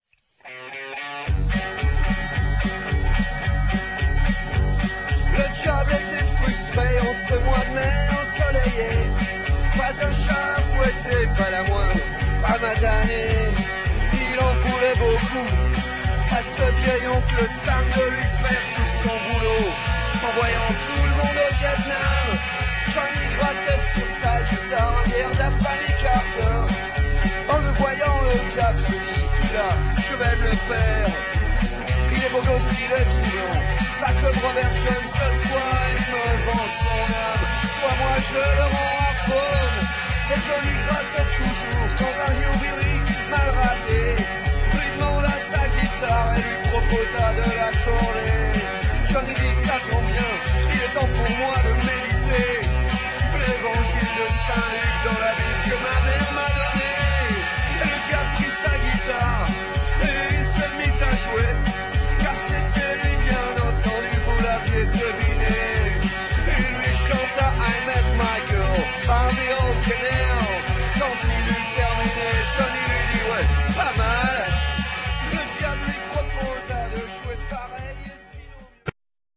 un groupe de country
bluegrass & country